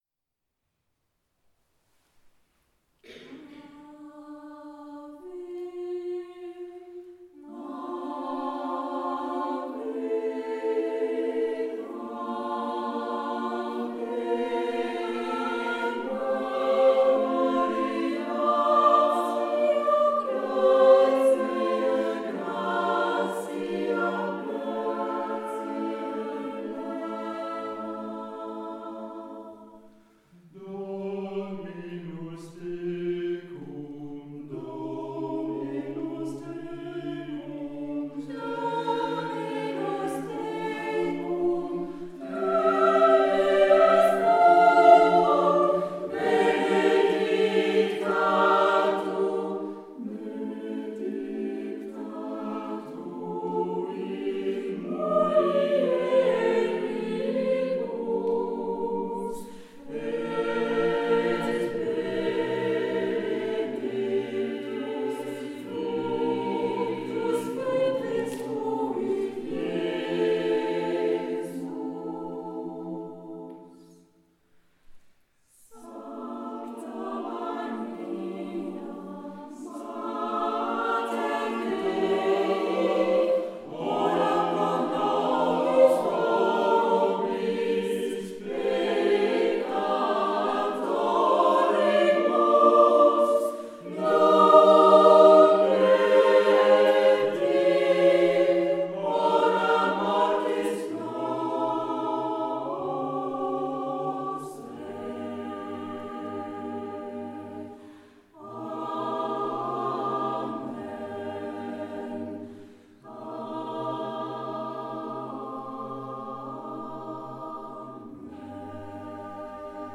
Pièces a cappella des XXe et XXIe siècles
Concerts donnés devant environ 300 auditeurs
Quelques extraits du concert de St-Suplice.